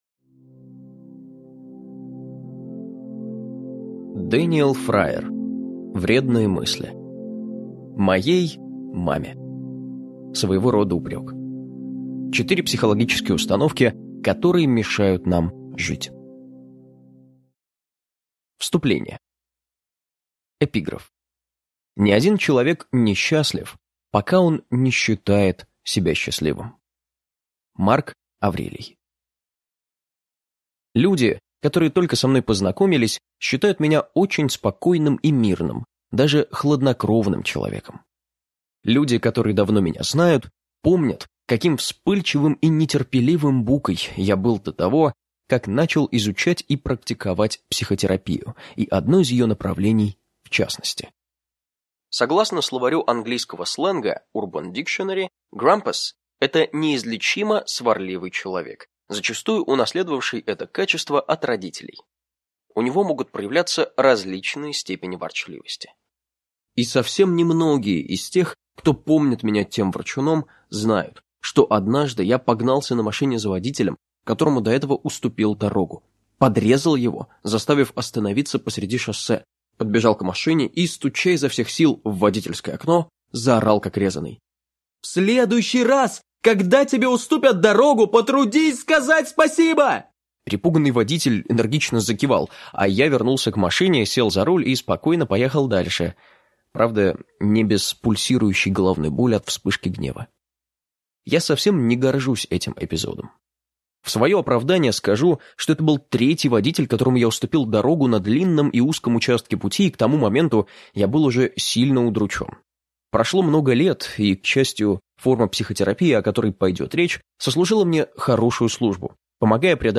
Аудиокнига Вредные мысли. Четыре психологические установки, которые мешают нам жить | Библиотека аудиокниг